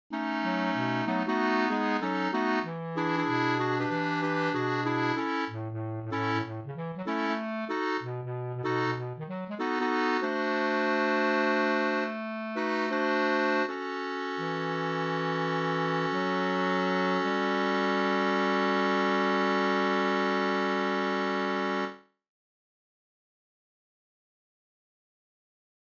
Key written in: A Major
How many parts: 4
Type: Barbershop
Comments: Meant to be performed in tempo, about
All Parts mix: